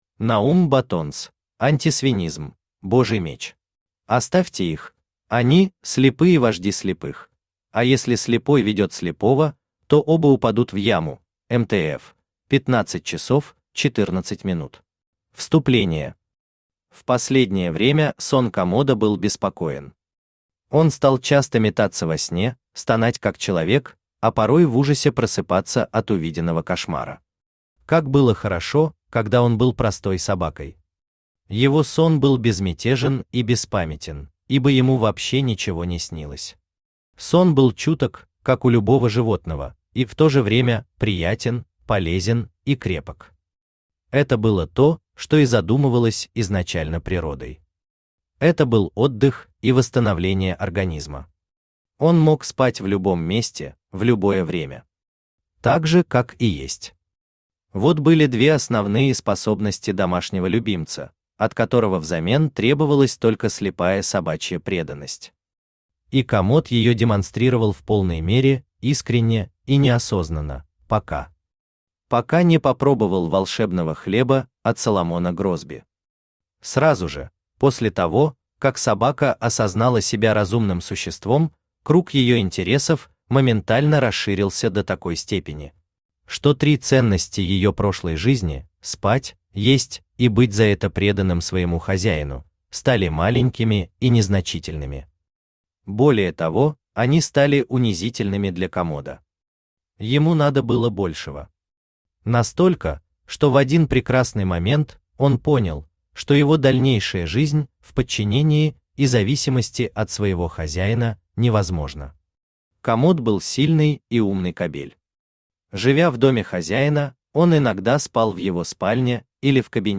Божий Меч Автор Наум Баттонс Читает аудиокнигу Авточтец ЛитРес.